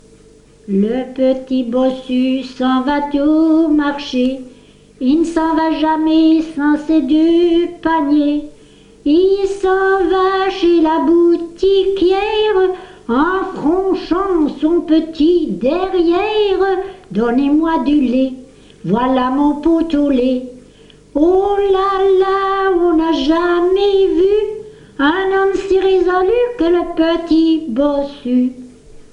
Genre : chant
Type : ronde, chanson à danser
Lieu d'enregistrement : Lessines
Support : bande magnétique